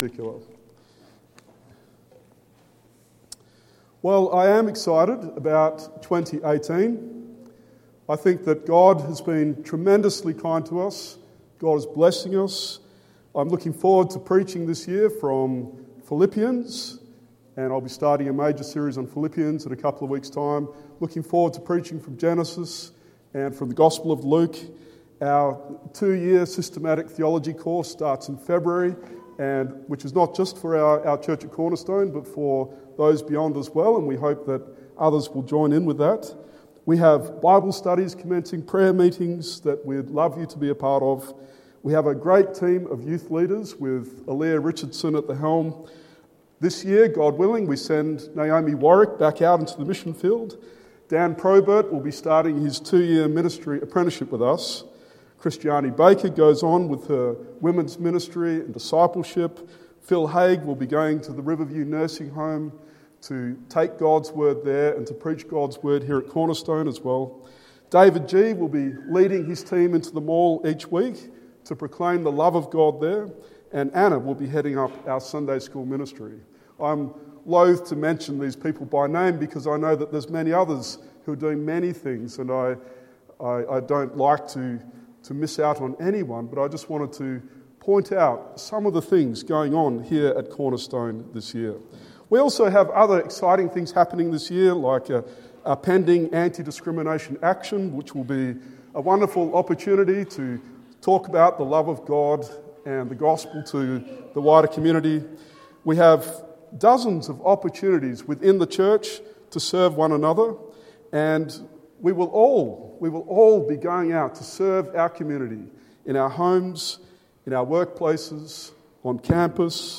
1 John 4:7-21 Sermon